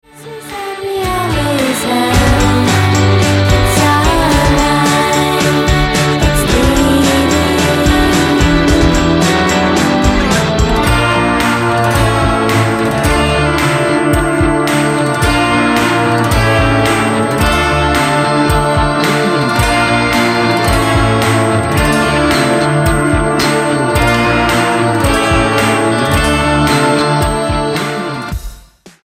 STYLE: Rock
uplifting music